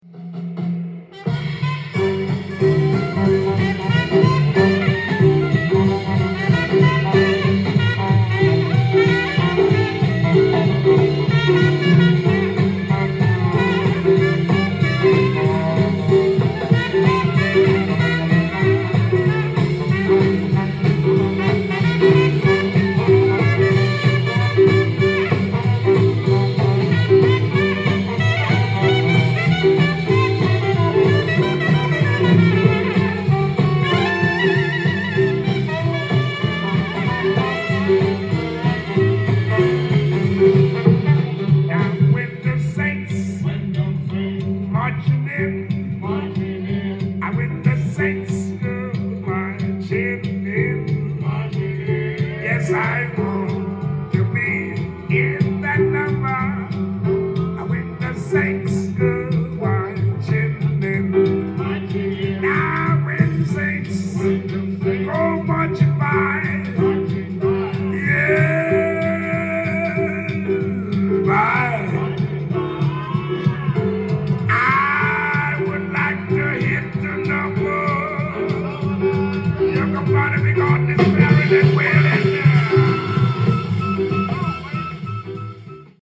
LP
店頭で録音した音源の為、多少の外部音や音質の悪さはございますが、サンプルとしてご視聴ください。